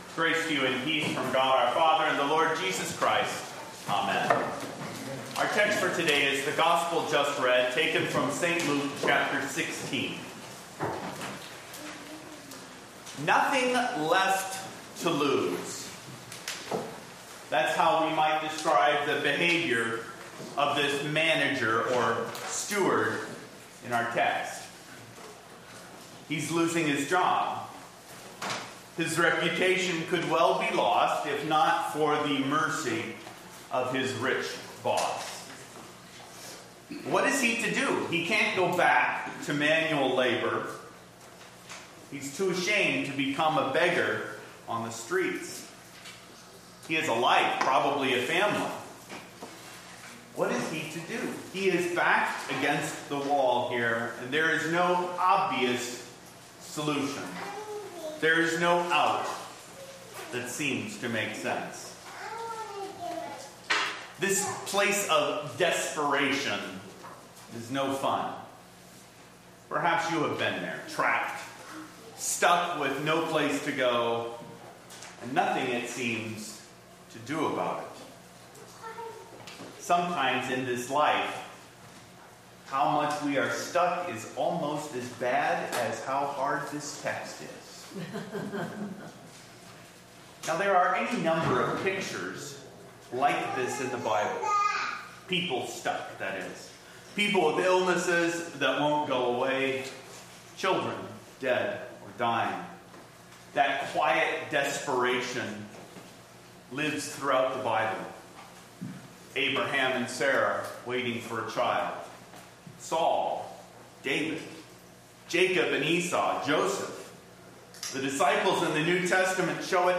sermon-9-22-13.mp3